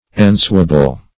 Ensuable \En*su"a*ble\, a. Ensuing; following.